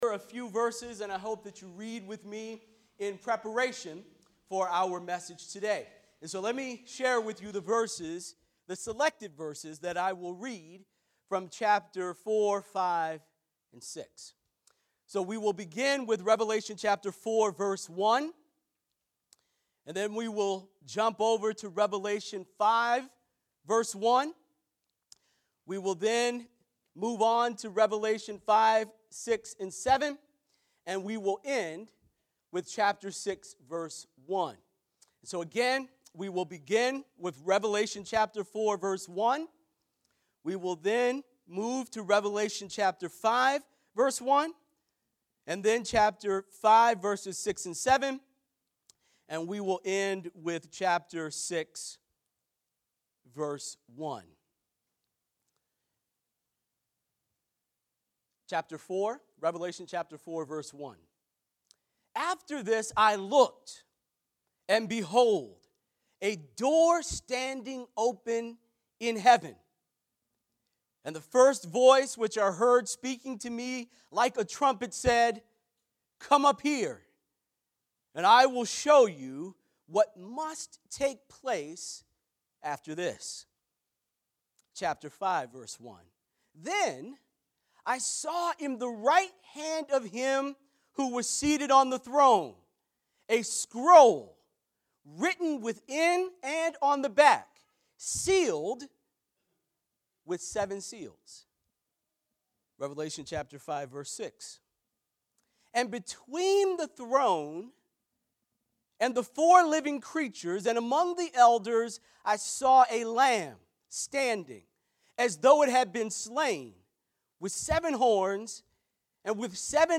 Crossroads Church of Hillside Sermons